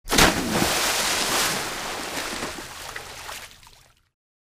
На этой странице собраны разнообразные звуки воды: журчание ручья, шум прибоя, капли дождя и плеск водопада.
Падение предмета в воду